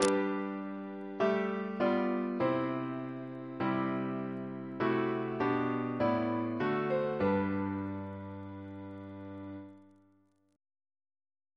Single chant in G Composer: George Thalben-Ball (1896-1987), Organist of the Temple Church Reference psalters: ACB: 241